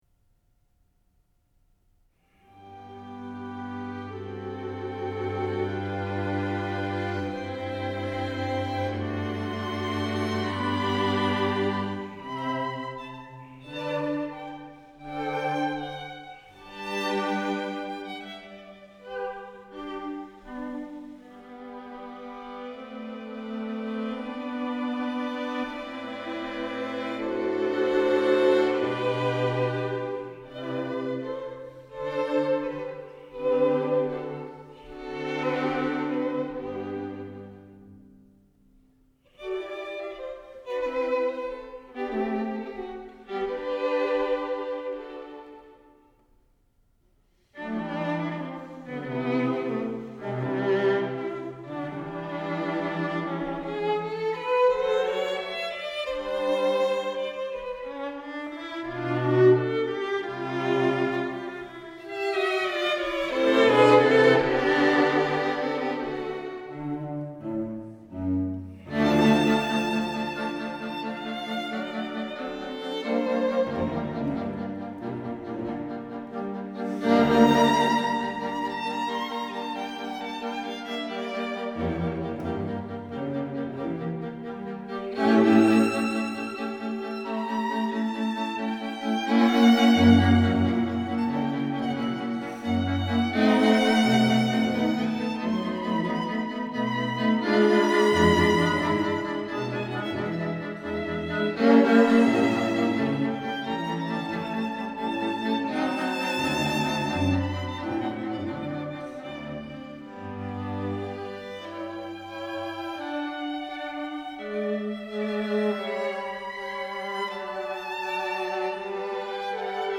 Mozart, String Quintet In D, K 593 – 2. Adagio